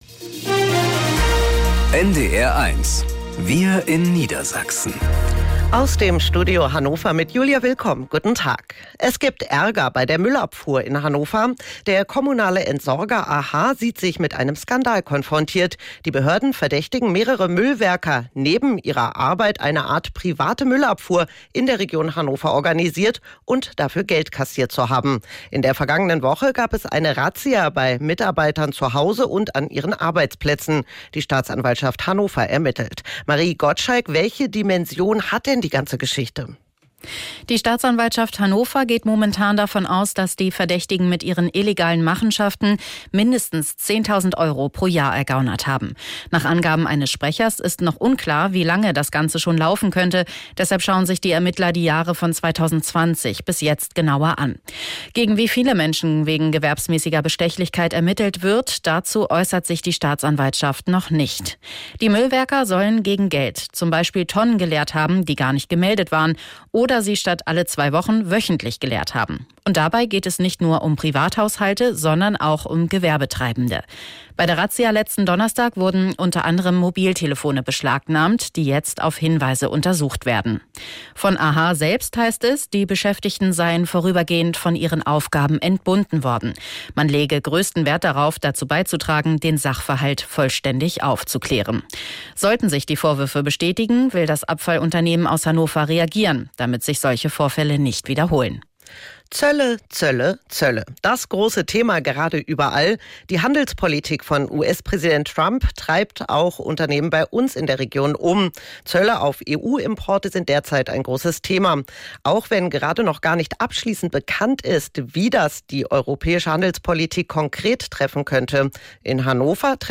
Wir in Niedersachsen - aus dem Studio Hannover | Nachrichten